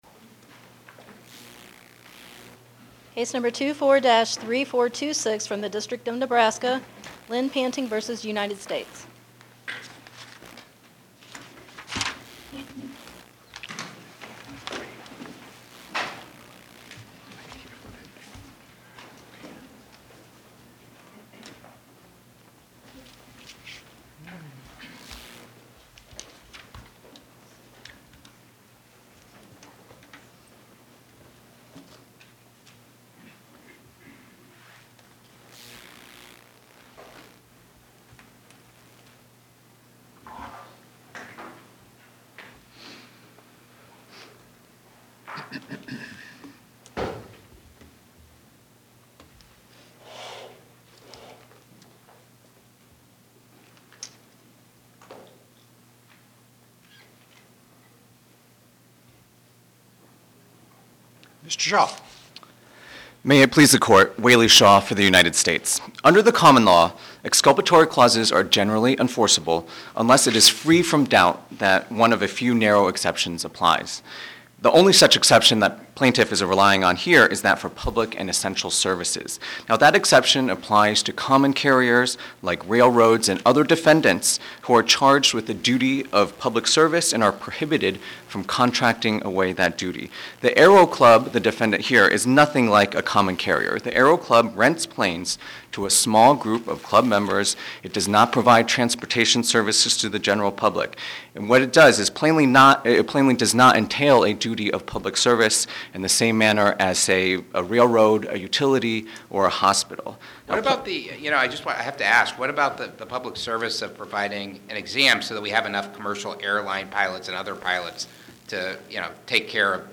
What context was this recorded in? Oral argument argued before the Eighth Circuit U.S. Court of Appeals on or about 11/19/2025